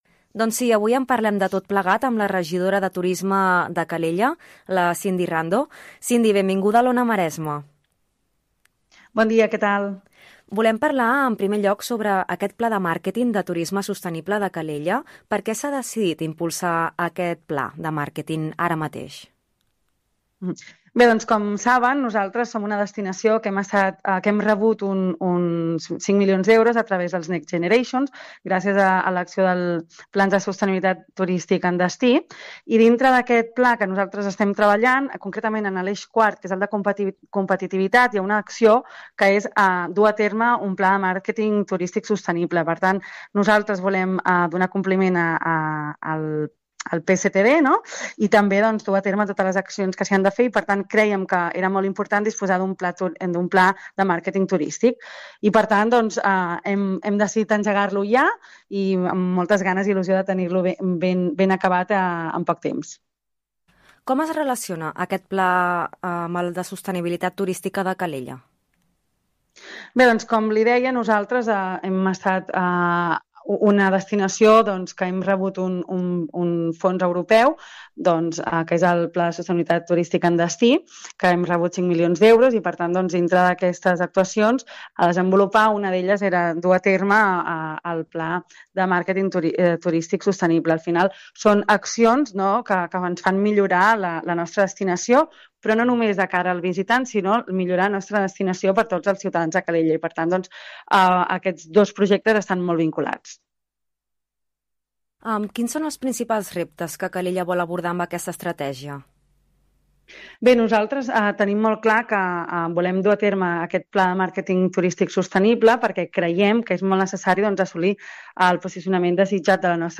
Ona Maresme: entrevista a la tinent d’Alcaldia de Turisme, Cindy Rando